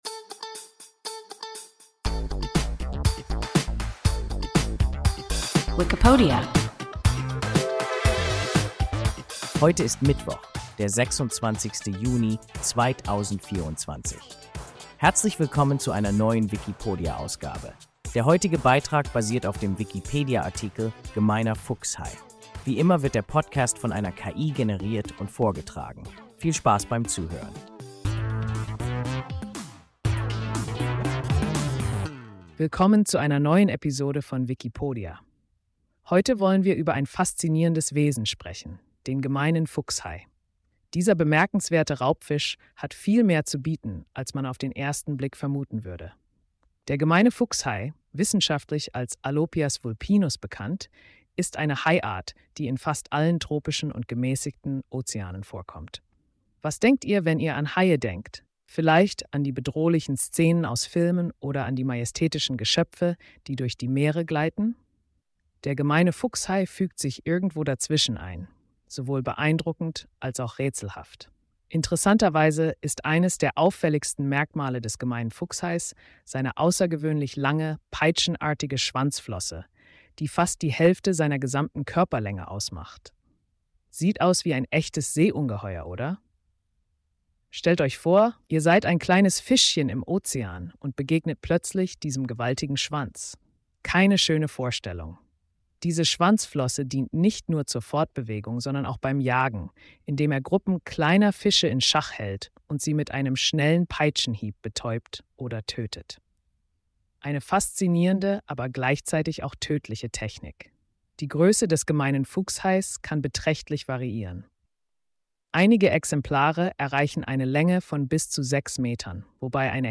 Gemeiner Fuchshai – WIKIPODIA – ein KI Podcast